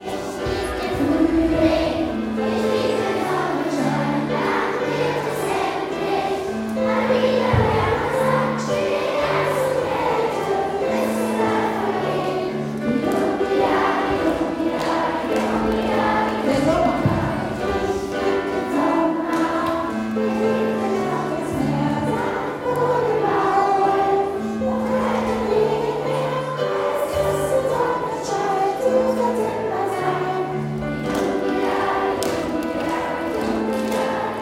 Monatssingen im Atrium